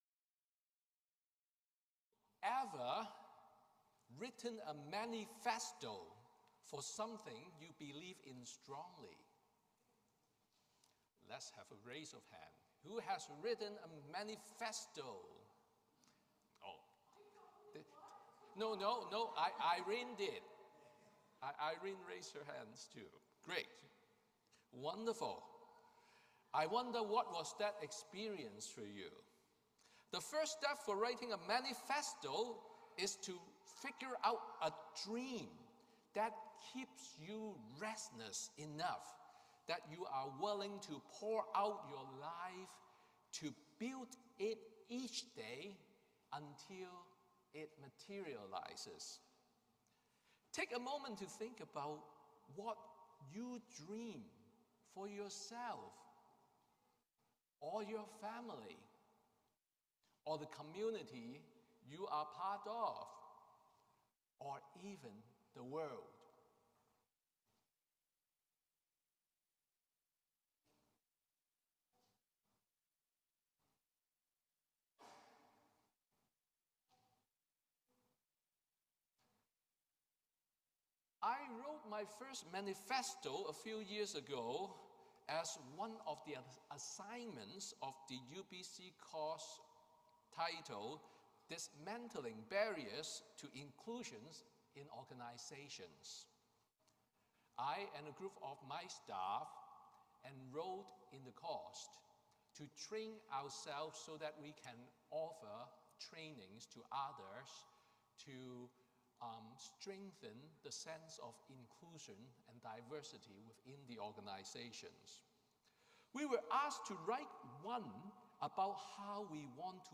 Sermon on Seventh Sunday after Pentecost